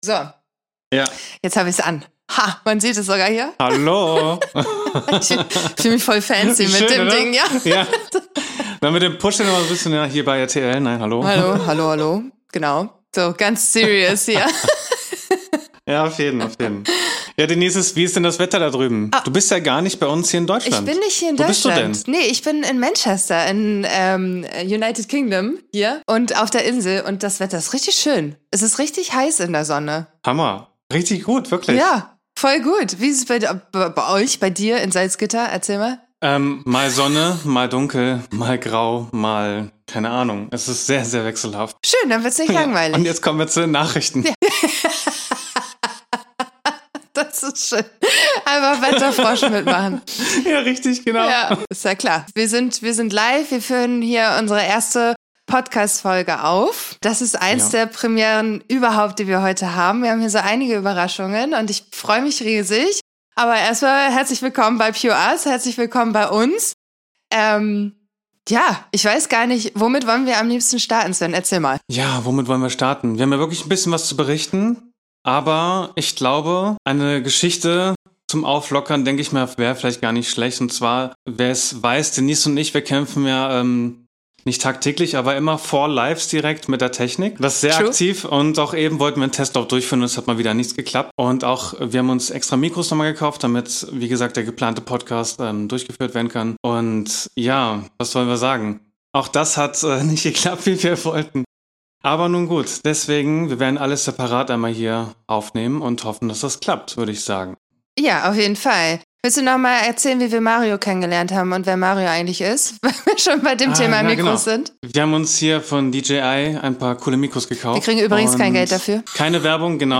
Dies ist unsere ERSTE Podcastfolge, aber bereits die siebte Liveshow von PureUs. Wir reflektieren über die letzten Monate und stellen unsere eigene Website sowie unsere Mastermind, namens "Visions Club" vor.